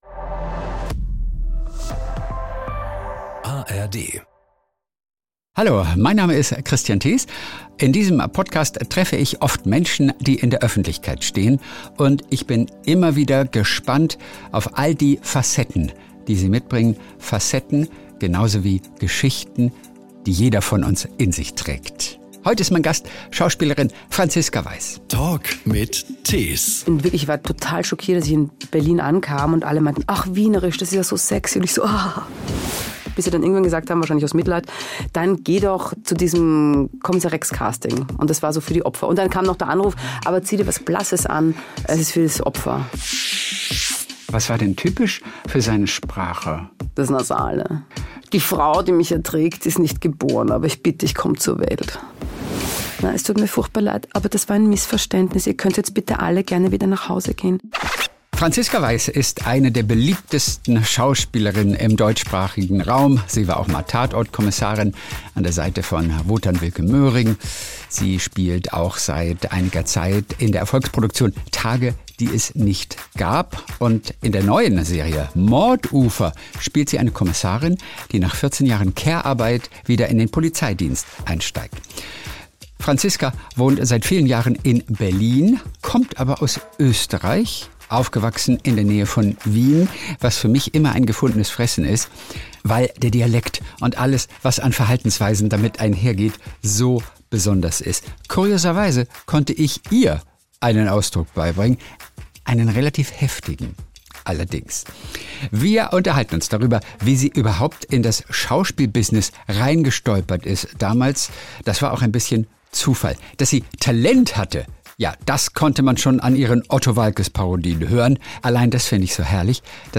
In diesem Podcast gibt es davon eine Kostprobe.